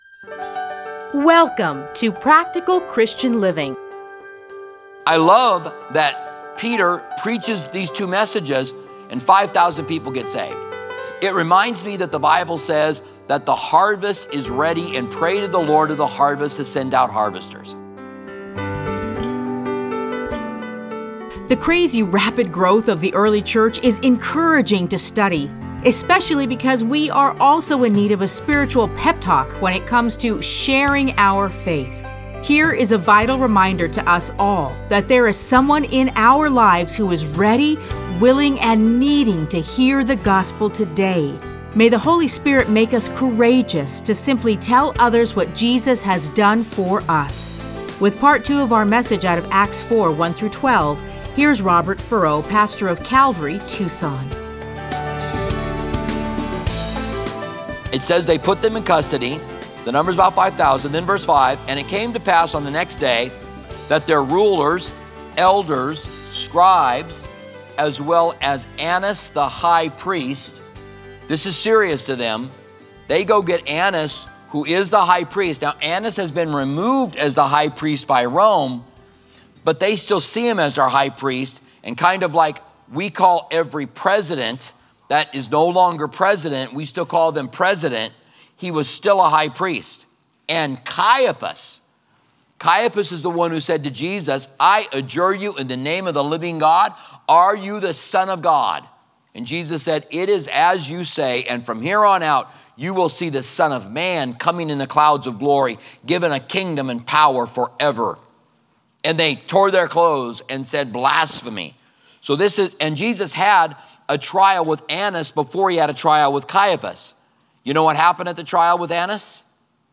Listen to a teaching from Acts 4:1-12.